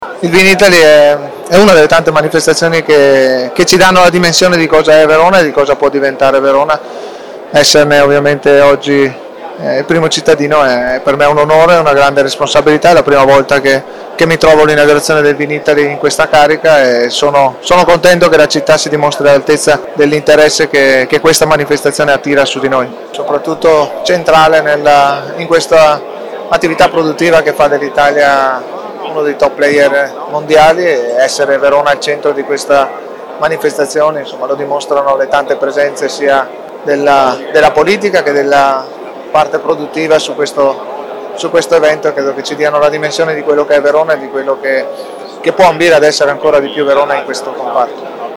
Sindaco-di-Verona-Damiano-Tommasi-allinaugurazione-del-Vinitaly.mp3